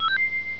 Fx [Coin].wav